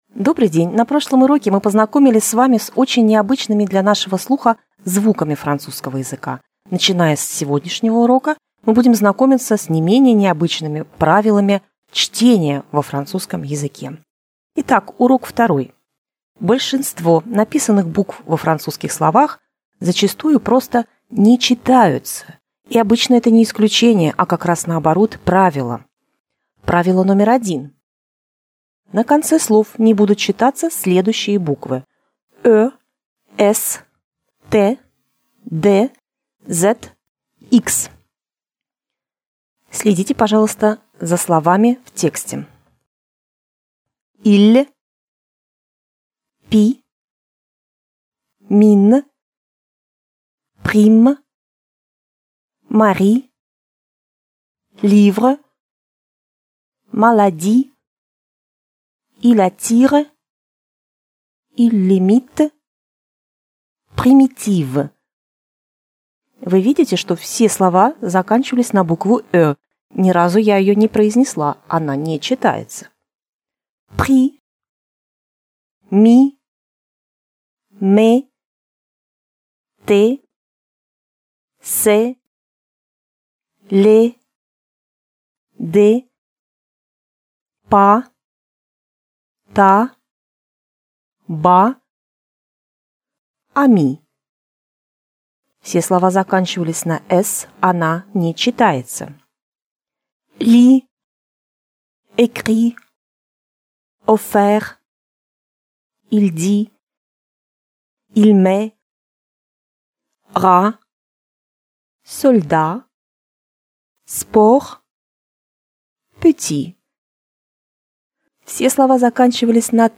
Фонетический курс французского языка — часть 2